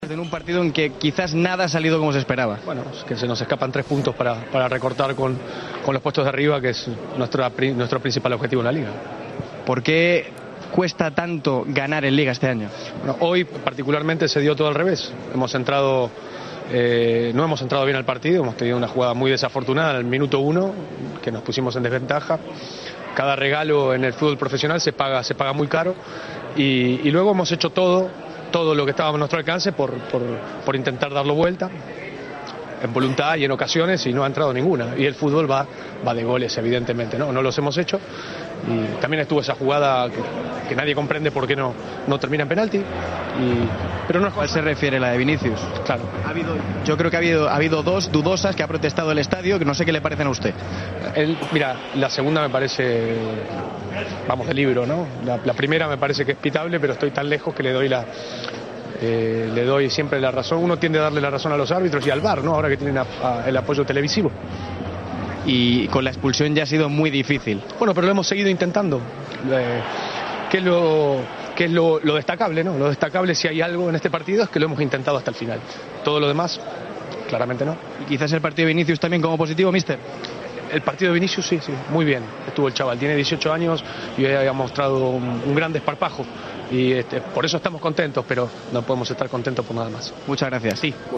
"La sensación es que debimos recortar tres puntos y no lo hicimos", comenzó Solari la rueda de prensa, antes de entrar en polémicas.